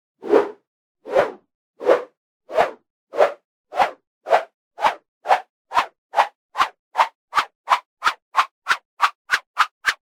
rope swoosh with added pseudo-doppler effect